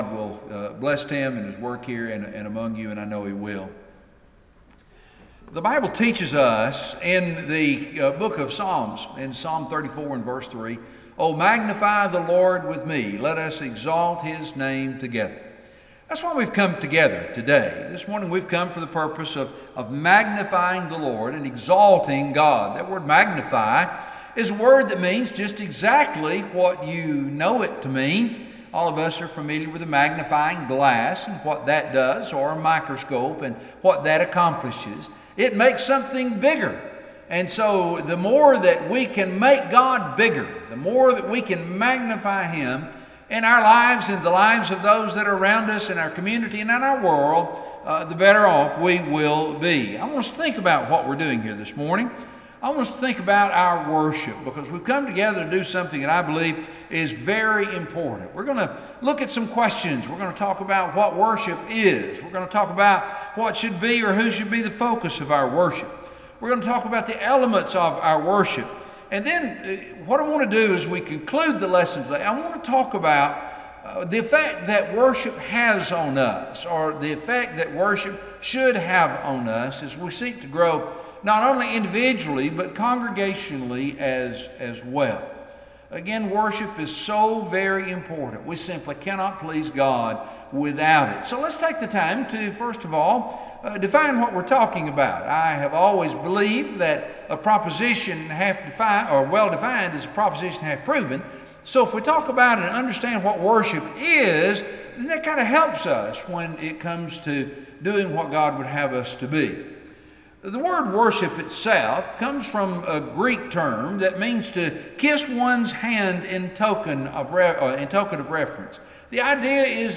Audio Sermons, Chapman Hwy church of Christ